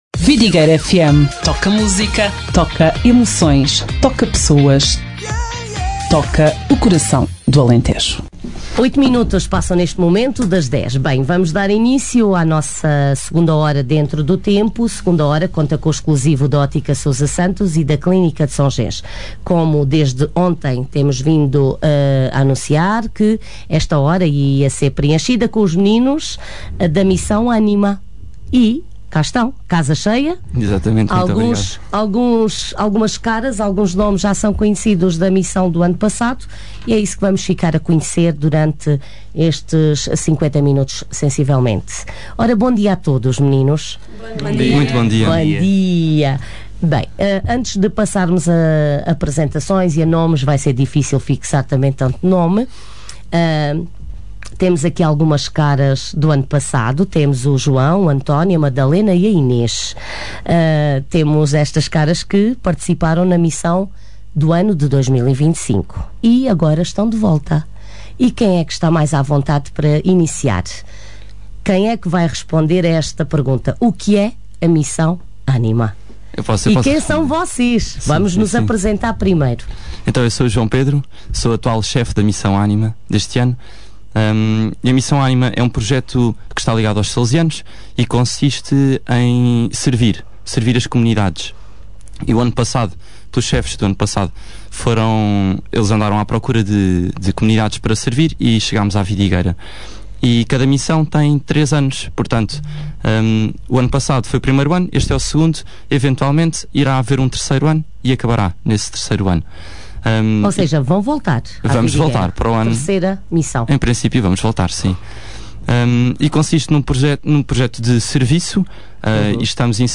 Não teve oportunidade de ouvir o programa da Rádio Vidigueira com alguns dos jovens que participaram na Missão ANIMA 2026?